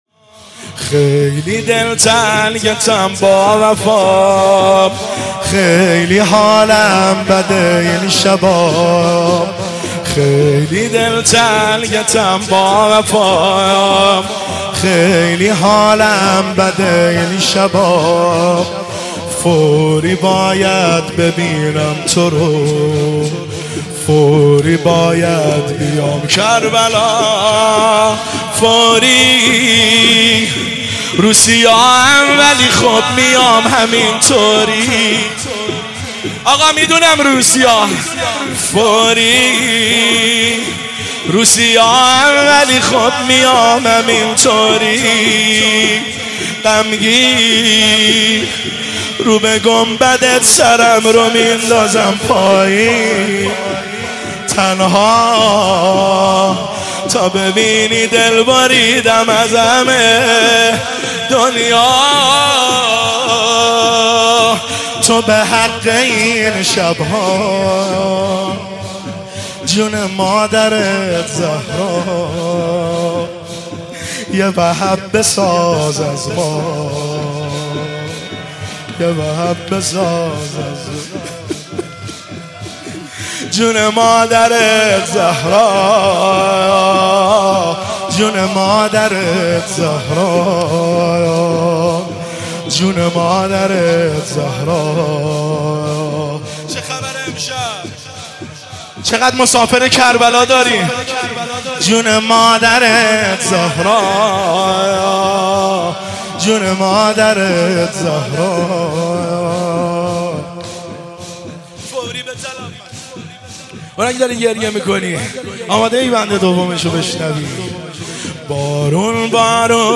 زمینه شب 20 رمضان المبارک 1403